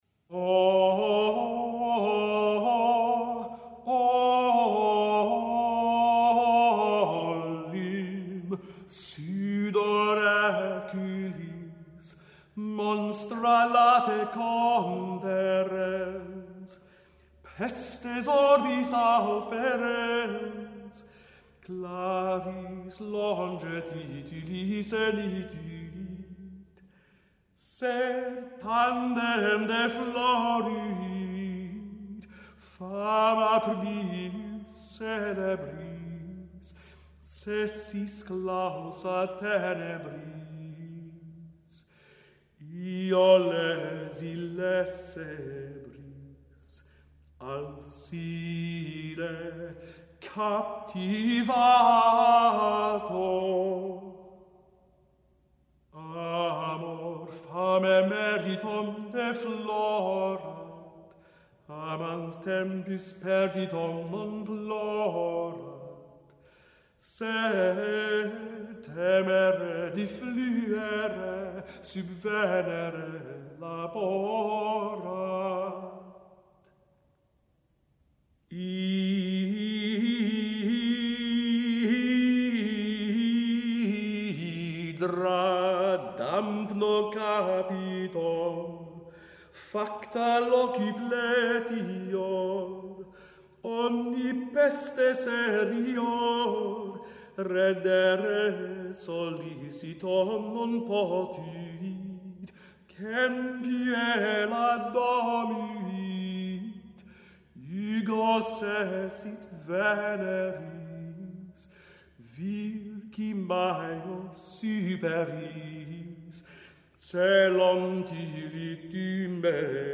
Stessa musica ogni due strofe con refrain ad ogni strofa.
L'andamento trocaico vede l'uso di neumi a due suoni (soprattutto clivis) quasi sempre in corrispondenza del tempo forte.
Da F, tutto a voce sola, ritmo libero.